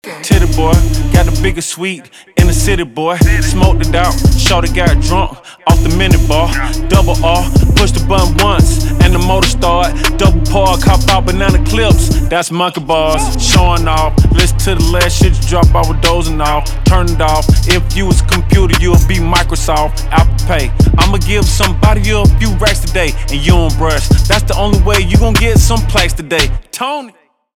рэп
хип-хоп
битовые , басы , качающие , крутые , жесткие